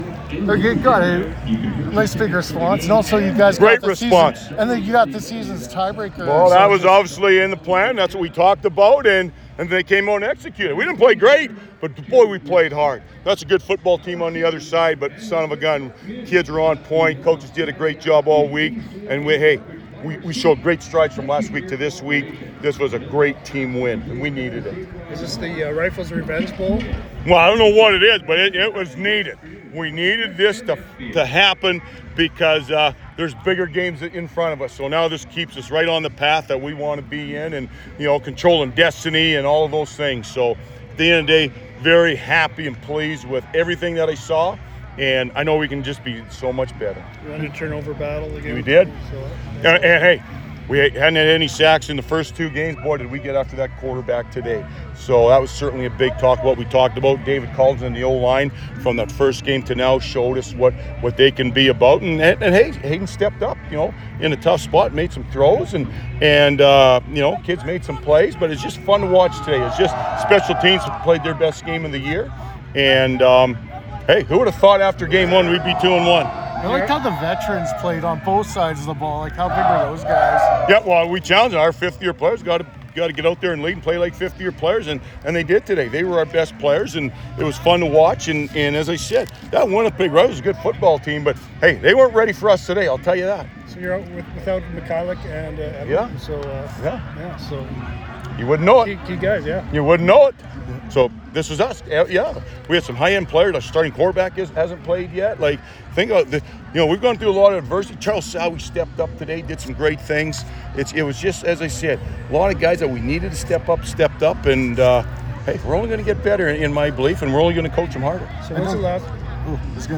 Media Scrum